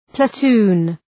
{plə’tu:n}
platoon.mp3